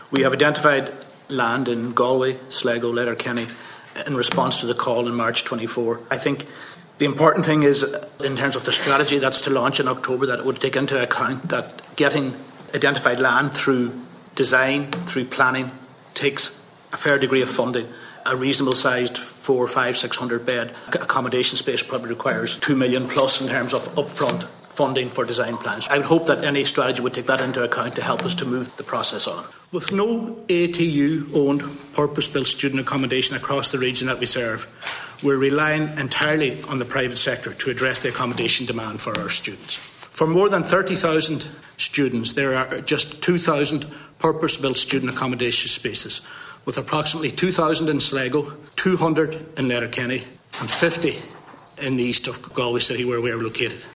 Technological Universities are before the Oireachtas Higher Education Committee this morning to highlight the need for student accommodation.